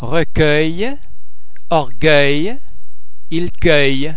eu oeu oe ue vowel-base simimlar to ir in ( sir)
The French [ ir ] [ er ] sound is similar to the vowel sound in English words like sir or herd. To be produced with almost no lip-rounding.